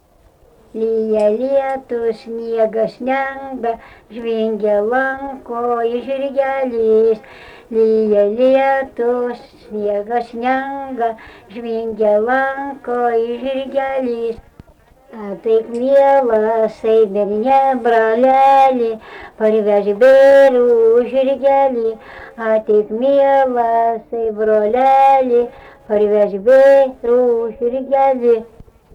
daina
Jūžintai
vokalinis